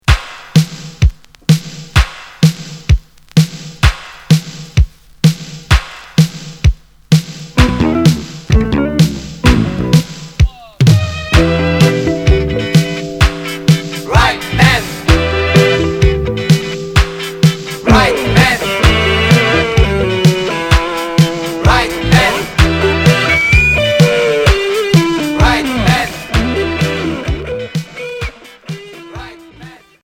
Rock new-wave